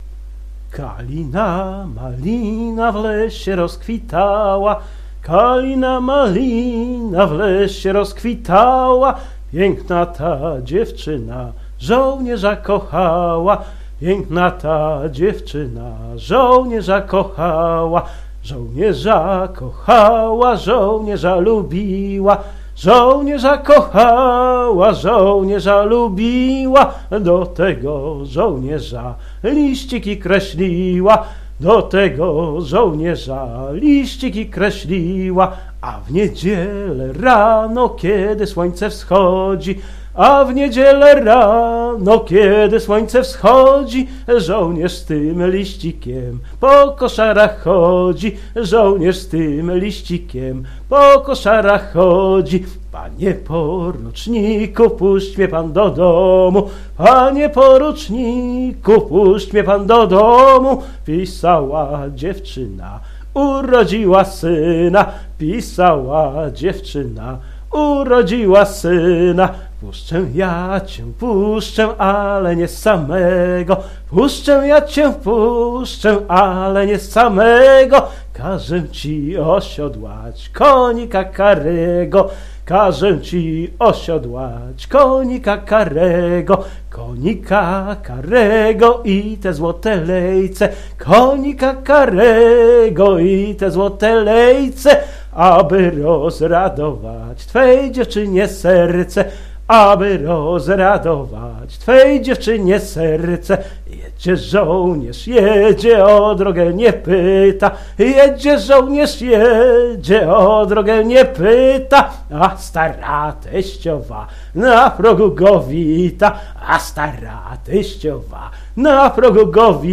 “Kalina Malina”, piosenka śpiewana przez  polskich żołnierzy, gdy po rozbiorach znowu byliśmy jedno.